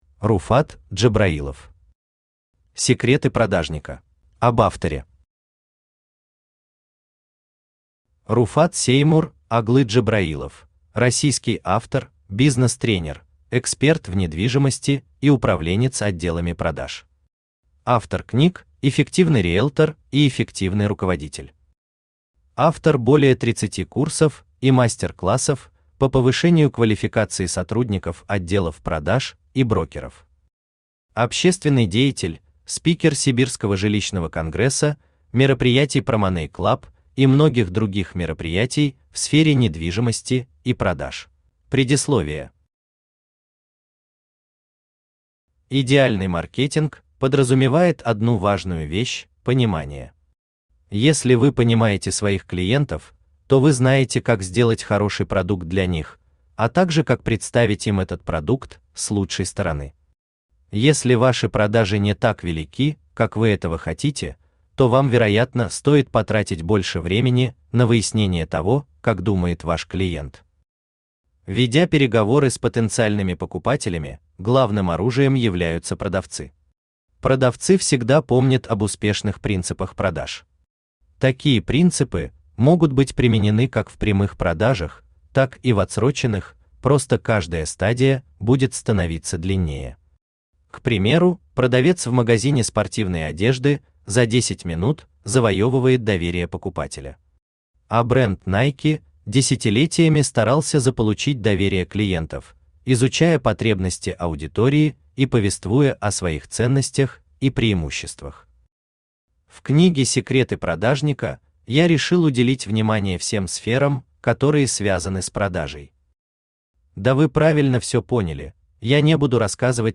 Аудиокнига Секреты Продажника | Библиотека аудиокниг
Aудиокнига Секреты Продажника Автор Руфат Джабраилов Читает аудиокнигу Авточтец ЛитРес.